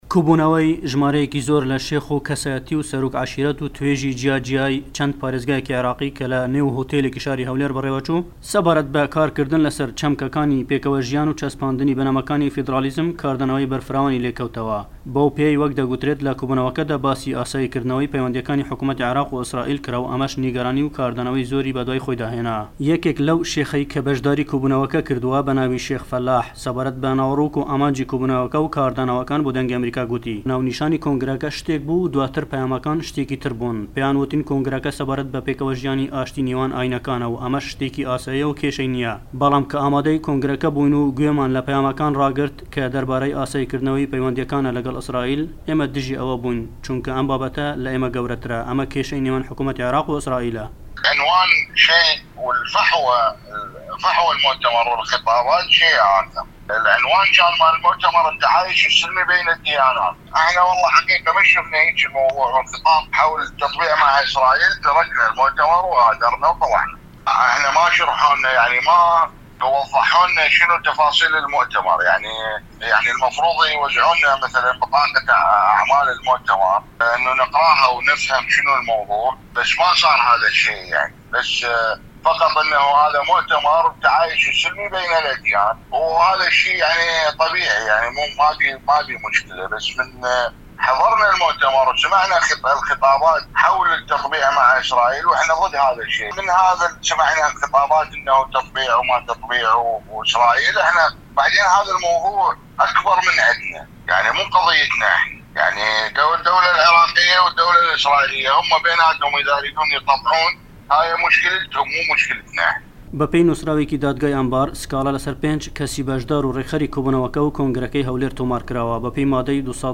راپۆرت سەبارەت بە کۆبوونەوەکەی هەولێر و پەیوەندی لە گەڵ ئیسڕائیل